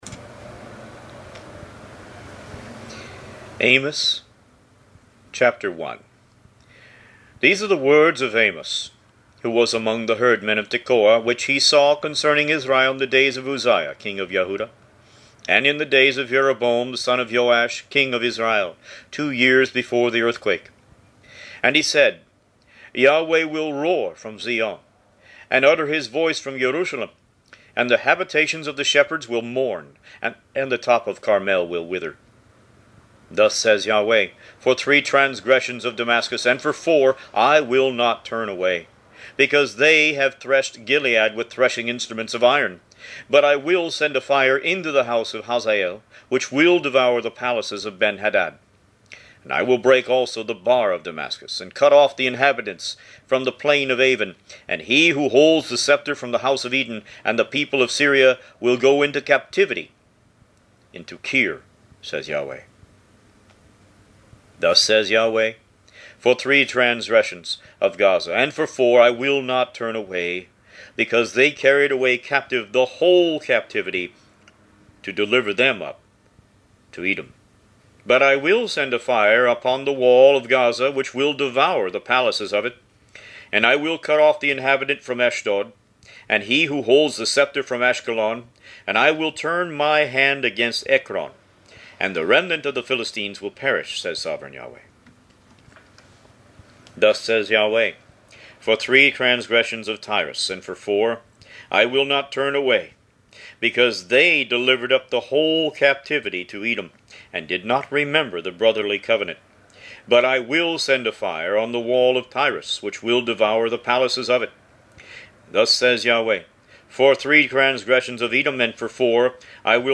Root > BOOKS > Biblical (Books) > Audio Bibles > Tanakh - Jewish Bible - Audiobook > 30 Amos